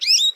animalia_goldfinch_2.ogg